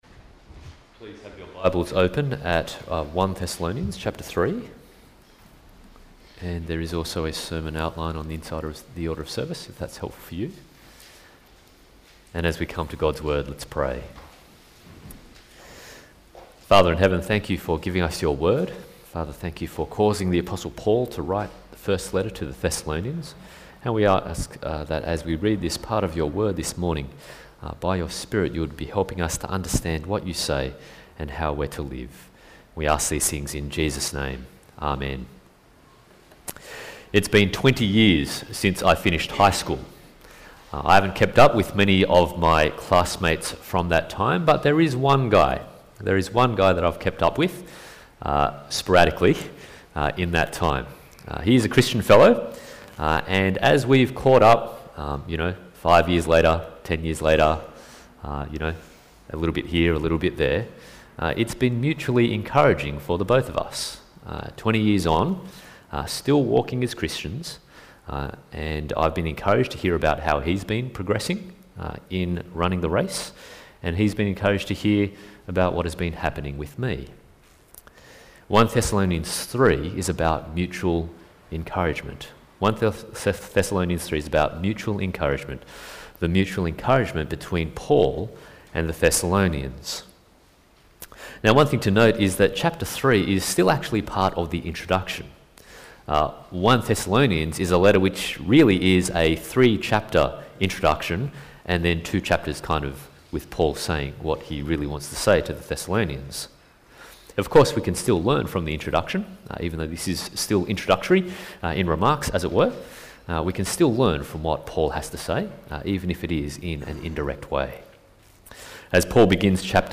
1 Thessalonians Passage: 1 Thessalonians 3:1-13 Service Type: Sunday Morning